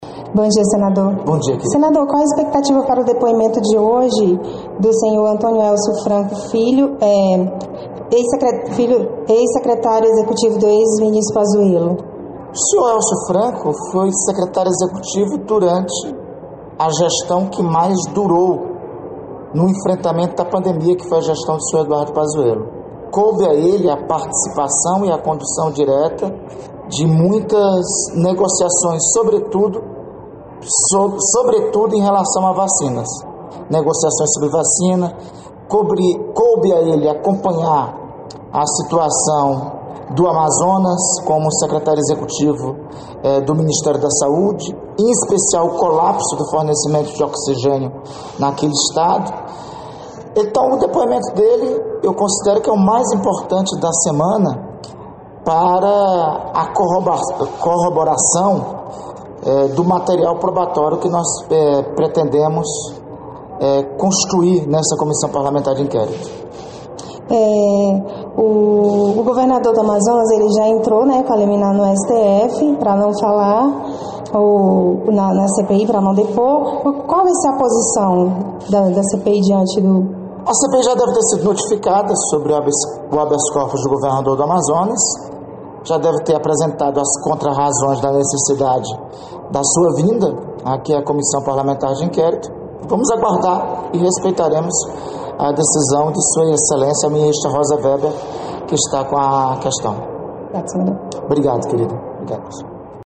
Entrevista com o vice-presidente da CPI da Pandemia, Randolfe Rodrigues